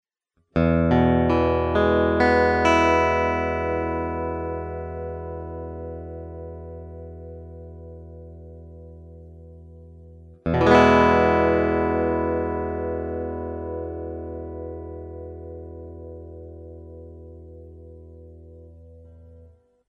Вот некоторые звуковые файлы (ничего особенного, просто арпеджио ми мажор сопровождаемый мажорным аккордом) с моим Macassar Ebony T5 в различных положениях (от 1 до 5):
Это прямо из гитары в мой 4-х дорожечный рекордер Fostex CompactFlash.